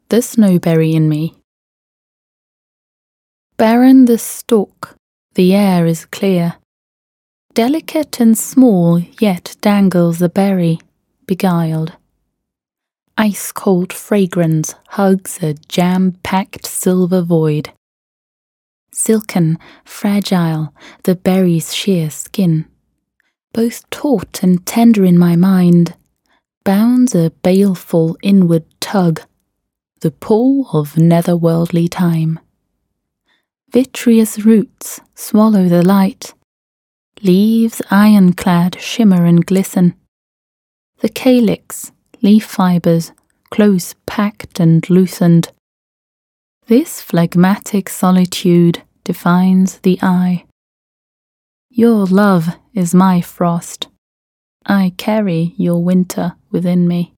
Poetry
Recorded at Sky Productions, Yau Ma Tei, Hong Kong